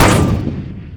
airboat_gun_energy1.wav